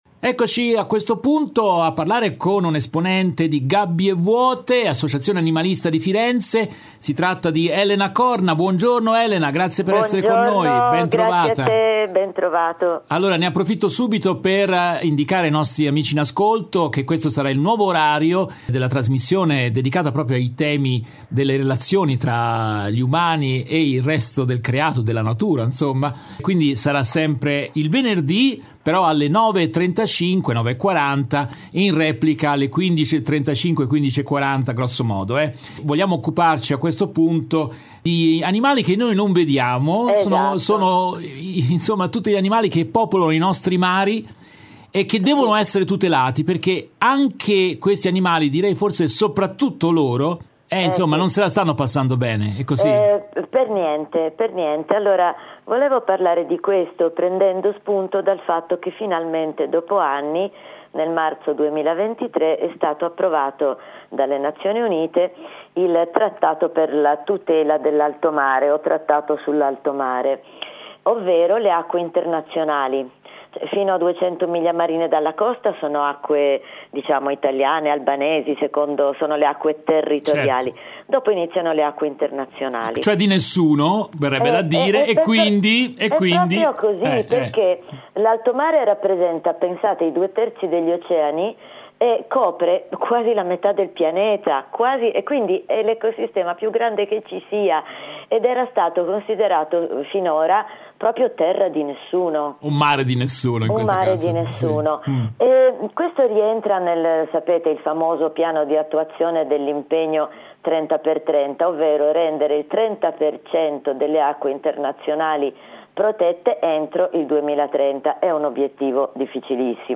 In questa nuova puntata tratta dalla diretta del 20 ottobre 2023
intervista